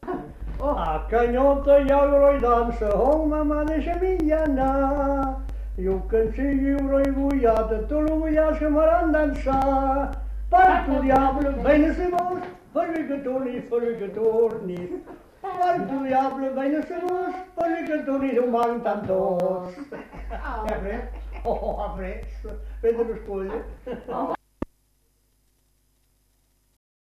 Aire culturelle : Agenais
Genre : chant
Effectif : 2
Type de voix : voix mixtes
Production du son : chanté
Danse : rondeau